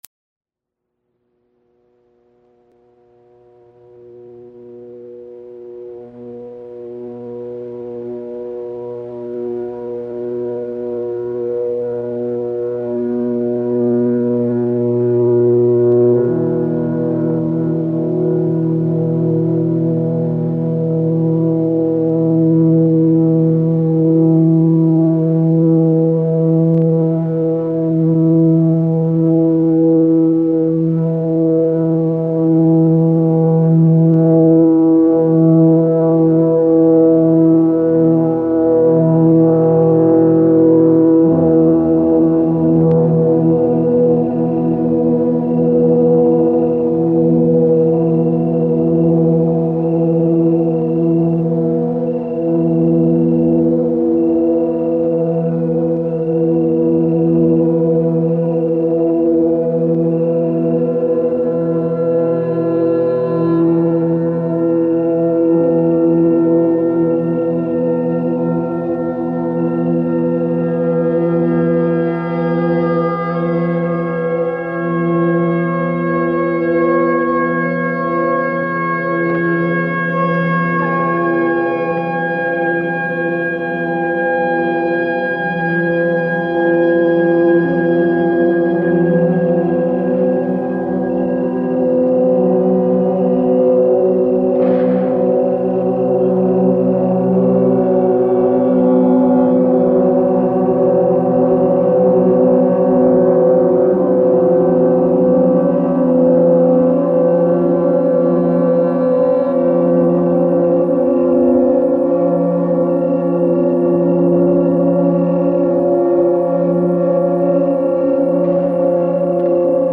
File under: Ambient